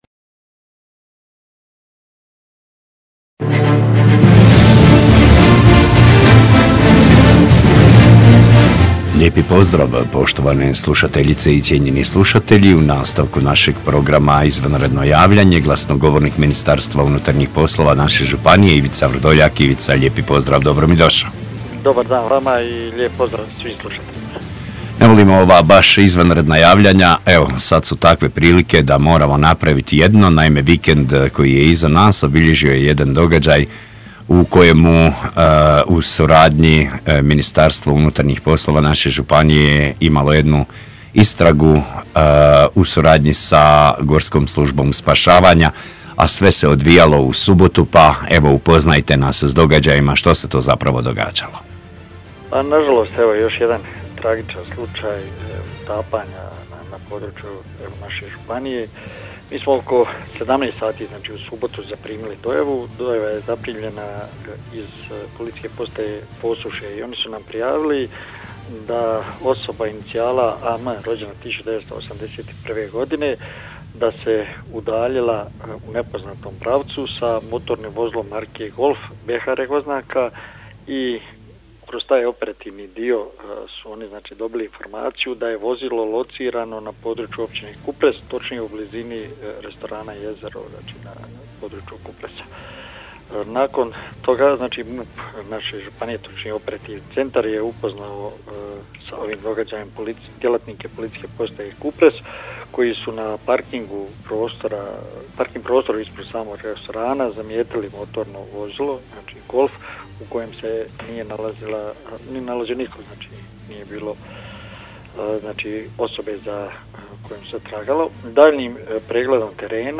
IZVANREDNO JAVLJANJE IZ MUP-a HBŽ O UTAPANJU MUŠKE OSOBE PROŠLI VIKEND